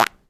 fart_squirt_01.wav